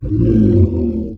MONSTER_Growl_Medium_10_mono.wav